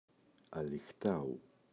αλυχτάου [aliꞋxtau]